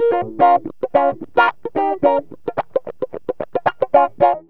GTR 12A#M110.wav